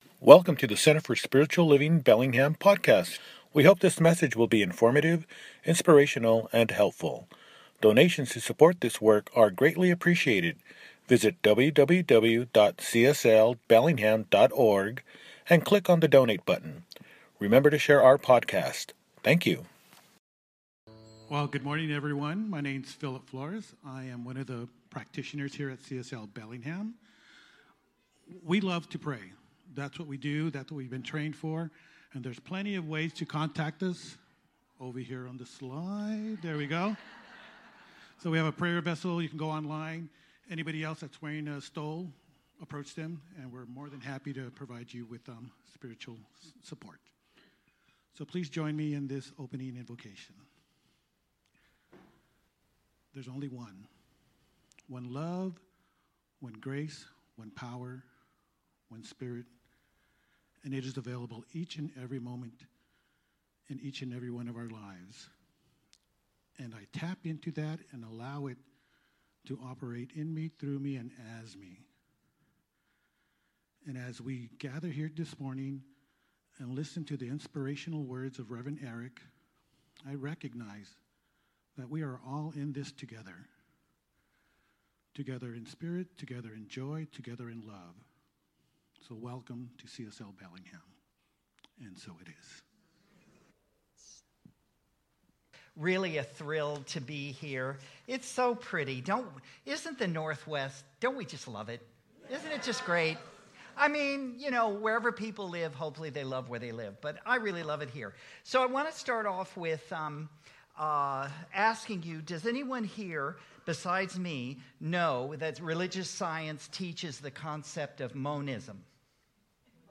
Life Beyond Limits – Celebration Service | Center for Spiritual Living Bellingham